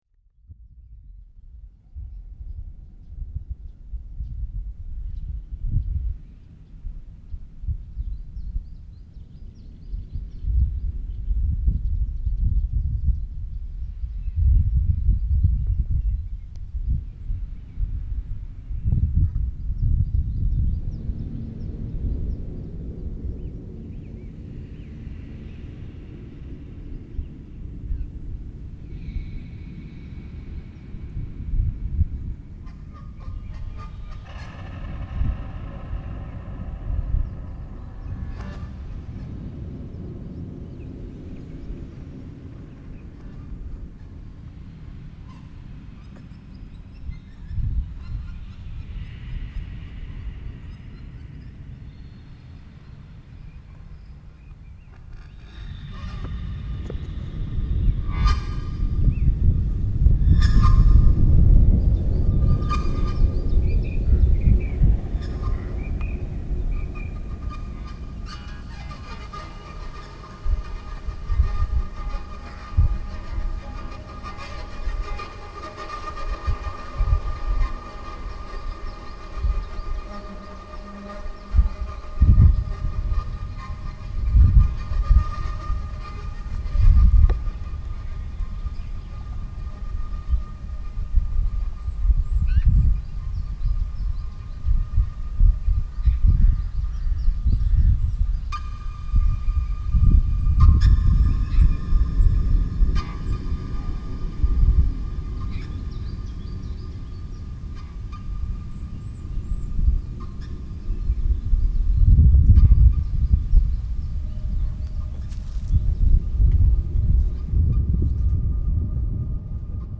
Cemetery in Kemerovo, Russia reimagined